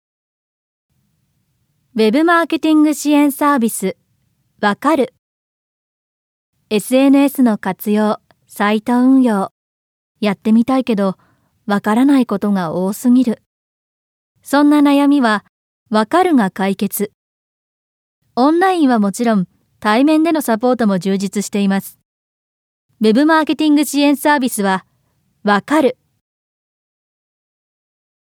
◆BtoB向けWebCM◆